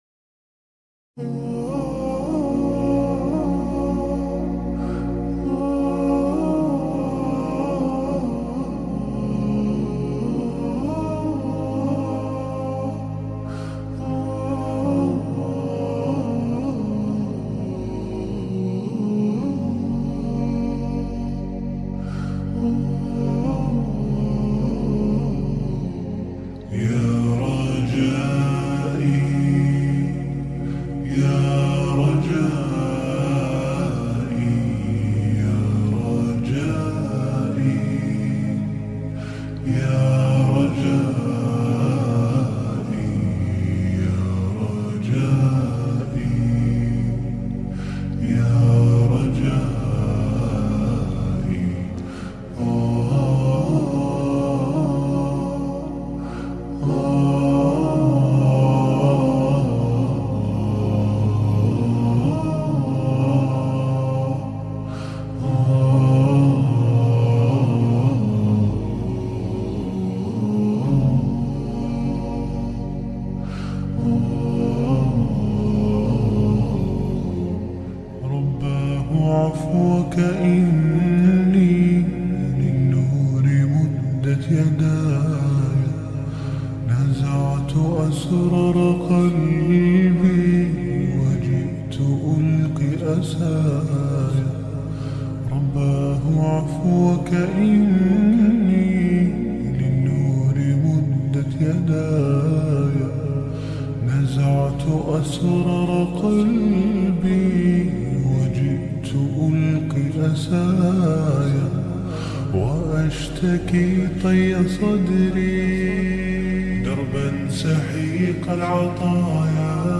Dr israr Ahmed the Most powerful motivational speech that will calm your heart..!!